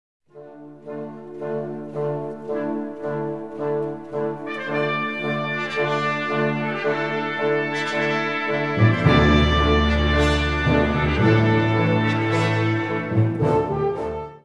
Sous-catégorie Musique de concert
Instrumentation Ha (orchestre d'harmonie)